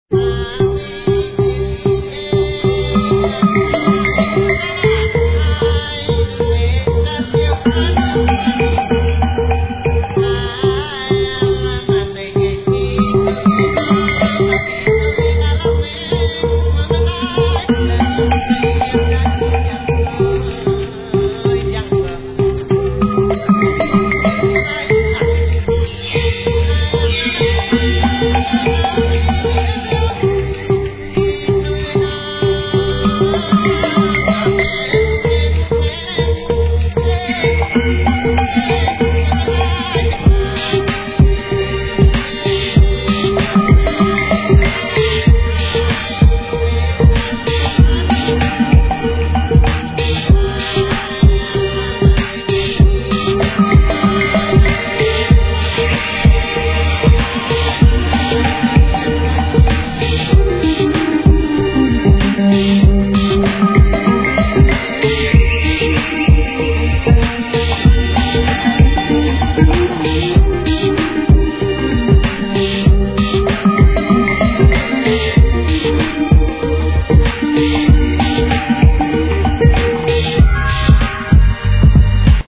HIP HOP/R&B
様々な楽器を自在に操り創り出すJAZZYでダウンテンポな仕上がりは怒渋!